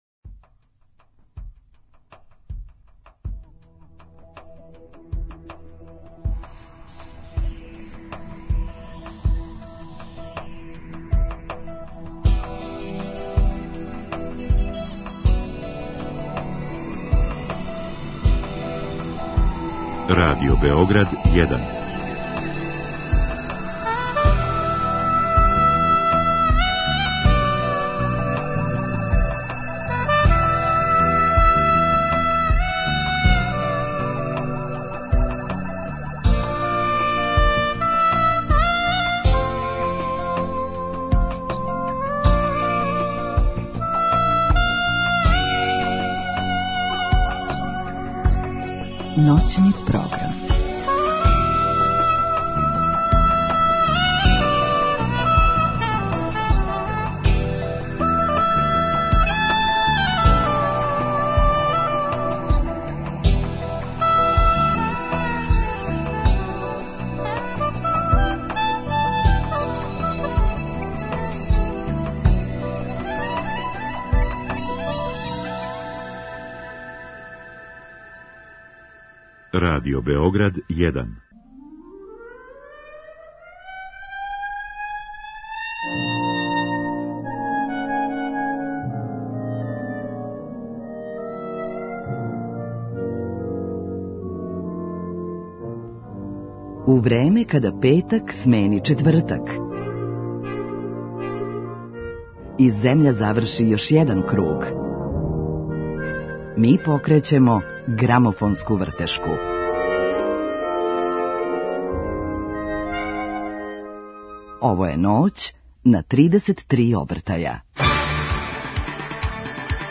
Гошћа Ноћи на 33 обртаја биће Бети Ђорђевић. Разговараћемо о њеној каријери, џез свиркама, али и о дуету са Рамбом Амадеусом са којим учествује на овогодишњем избору за представнику за Евровизију.
У другом сату пуштамо пет најслушанијих песама са Билбордове листе из прошлости, а емисију завршавамо новитетом на винилу.